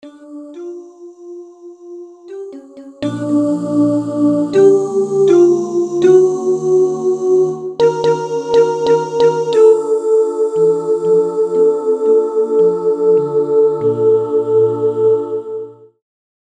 synth voices & a piano